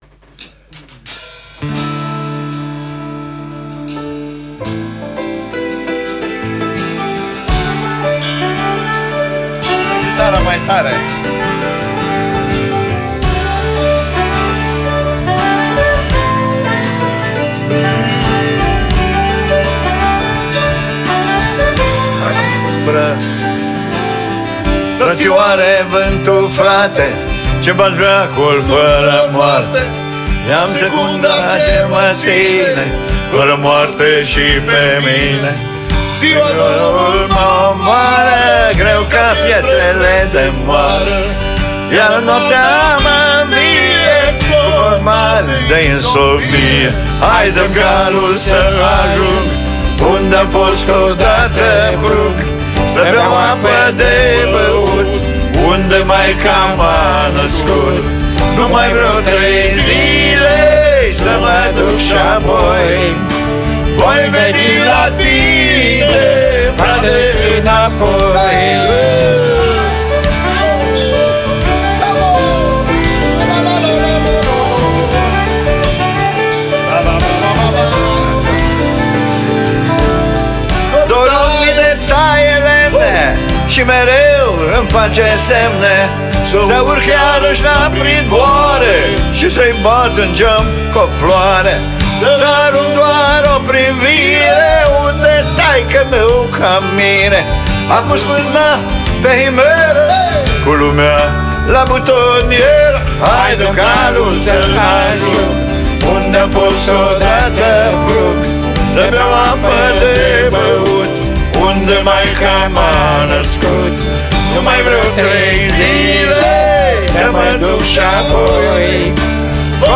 Pian
Chitara
Percutie